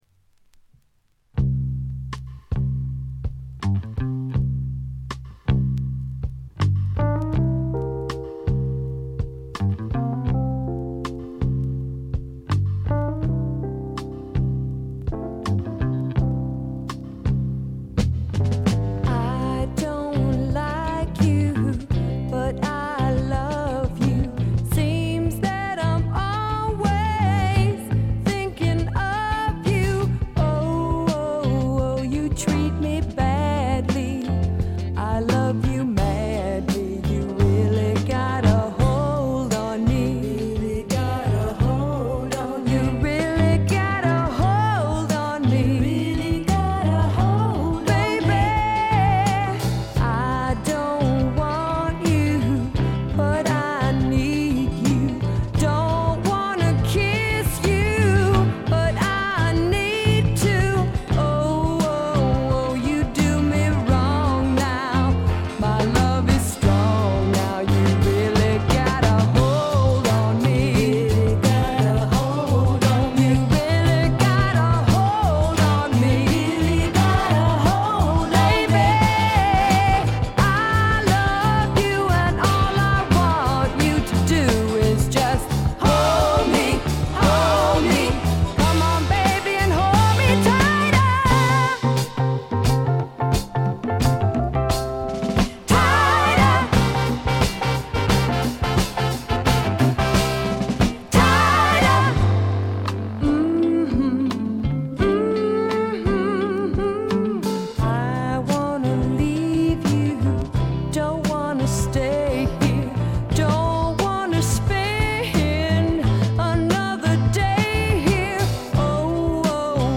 軽微なチリプチ少々。
ソウル系のカヴァーでは力強いシャウトで迫力のある歌声を聴かせてくれます。
スワンプ系女性ヴォーカル・アルバムの快作です。
試聴曲は現品からの取り込み音源です。